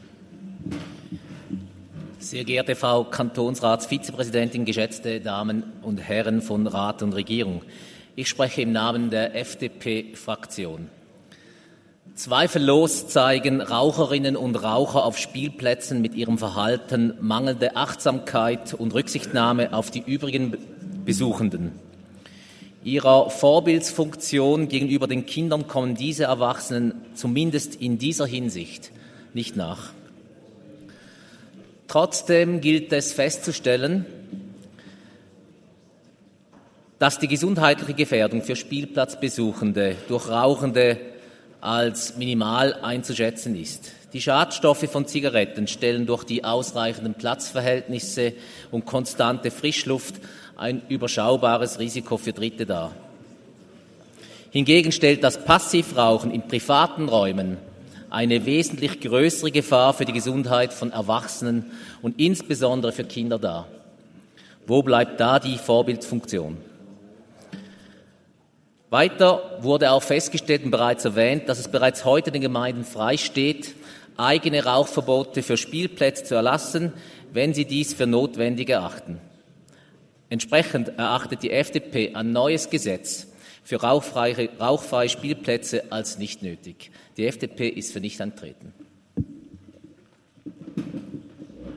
Session des Kantonsrates vom 27. bis 29. November 2023, Wintersession
27.11.2023Wortmeldung